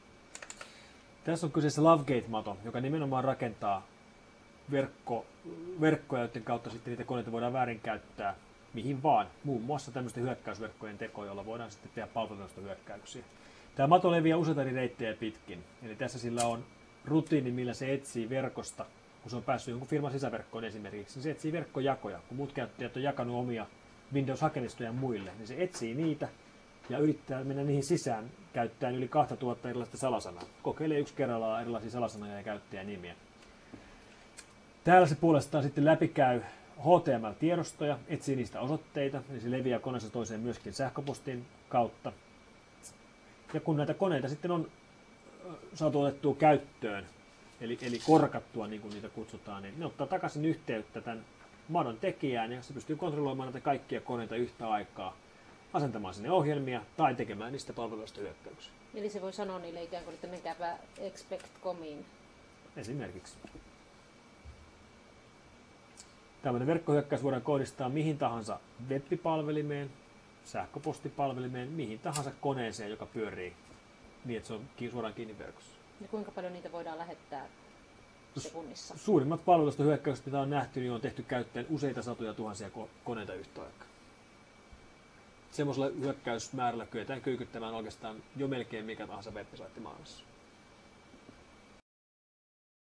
F-Securen tutkimusjohtaja Mikko Hyppönen kertoo Lovegate madosta.